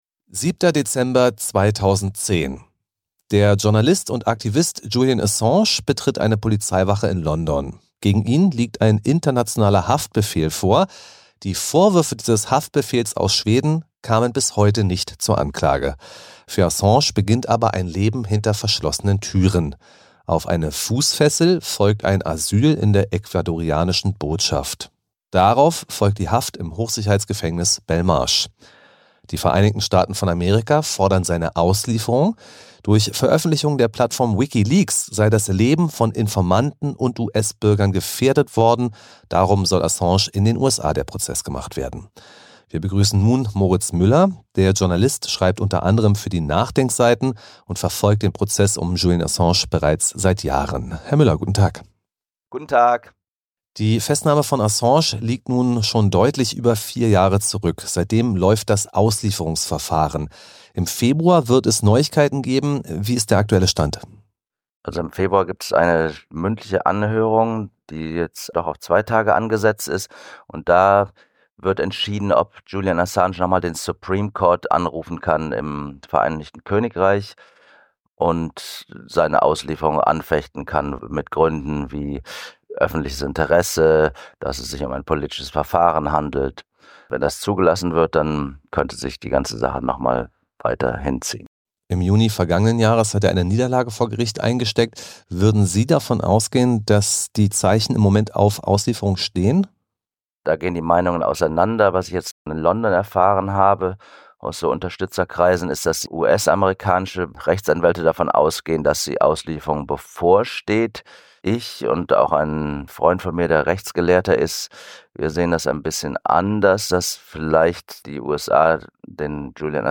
Interview zur Lage von Julian Assange auf Kontrafunk